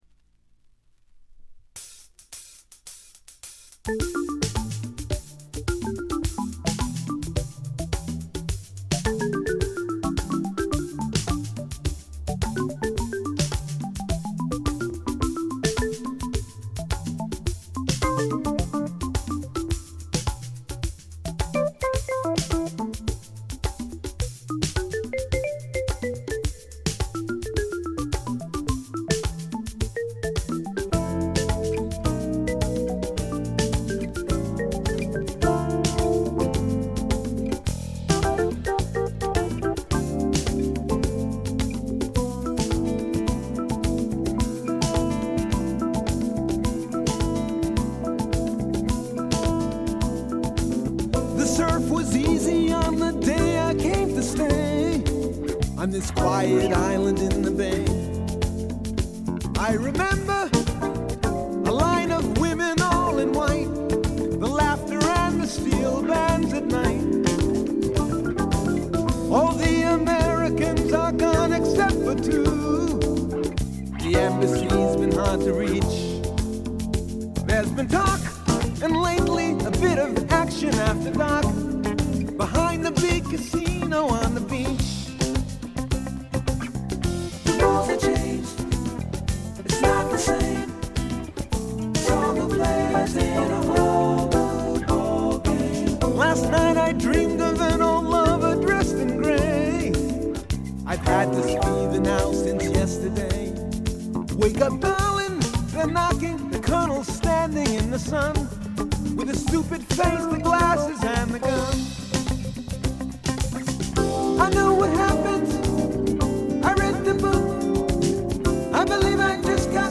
ホーム > レコード：ポップ / AOR
部分試聴ですが、ごくわずかなノイズ感のみ。
試聴曲は現品からの取り込み音源です。